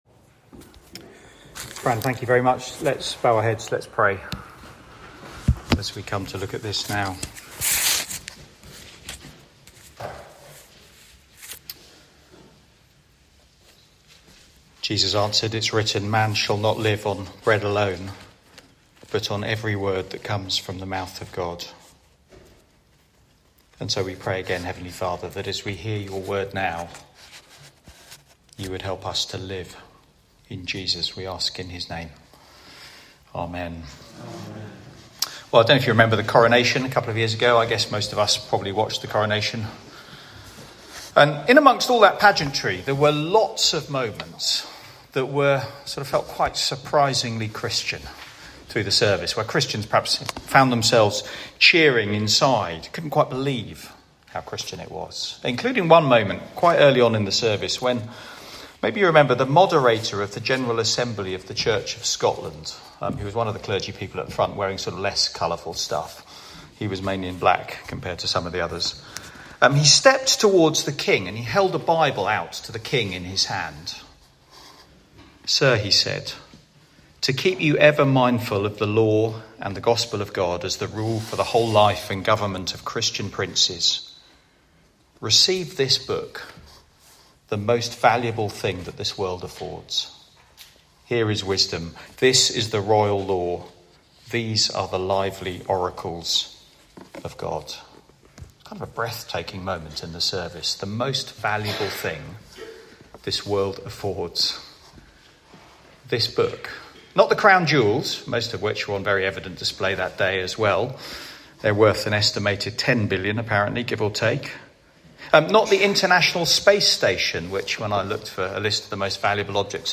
Theme: The Bible - what it's like Sermon